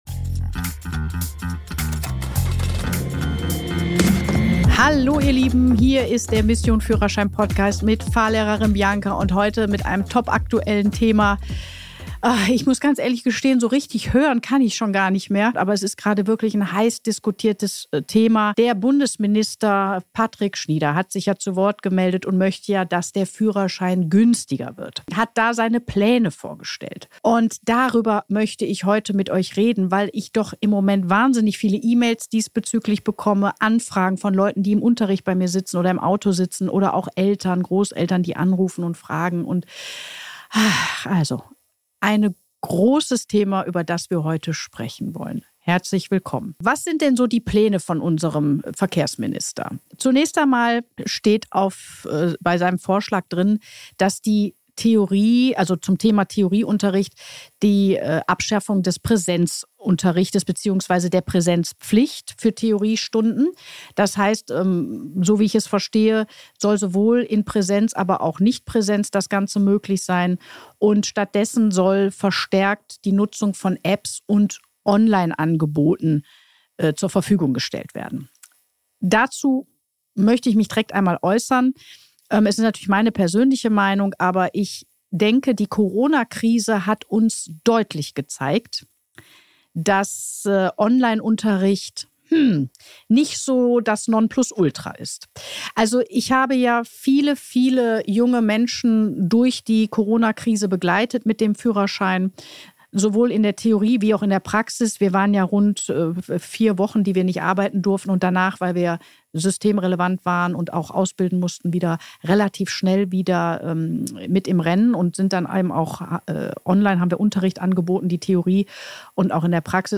Beschreibung vor 5 Monaten In dieser Solo-Folge nehme ich kein Blatt vor den Mund. Verkehrsminister Patrick Schnieder hat Reformvorschläge zur Fahrausbildung vorgestellt – angeblich, um den Führerschein günstiger zu machen.